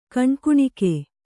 ♪ kaṇkuṇike